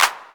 clap.ogg